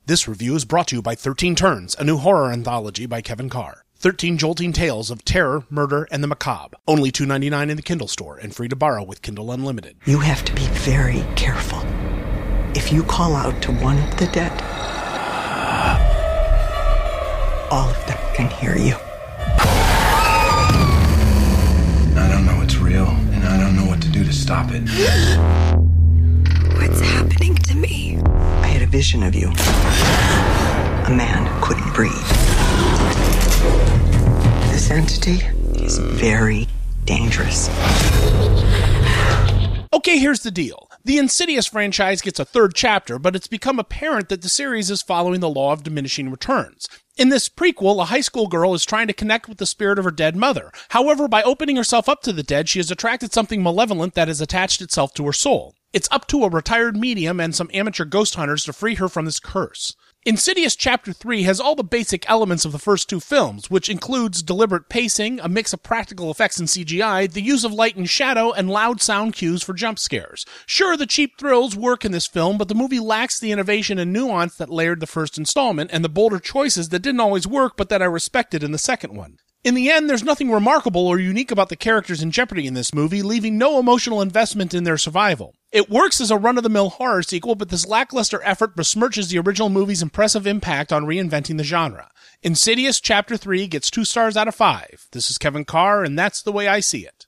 ‘Insidious: Chapter 3’ Movie Review